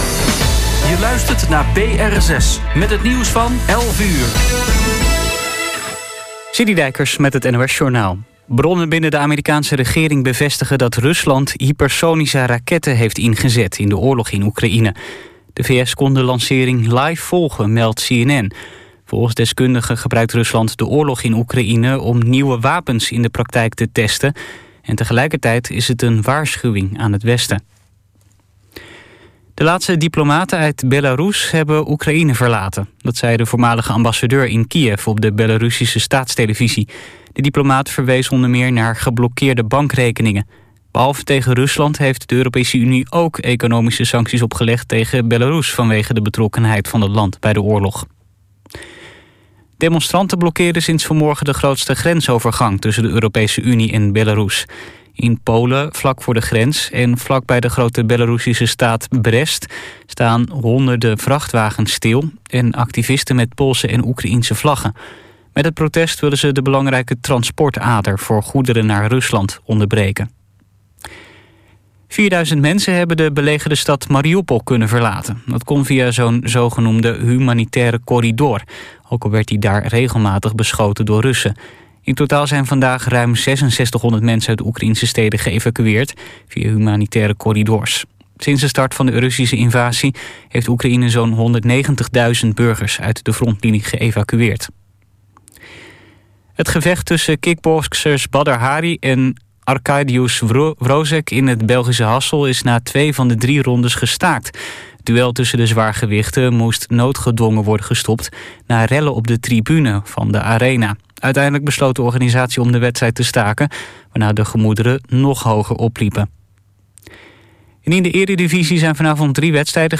alt saxofoon
contrabas
tenor saxofoon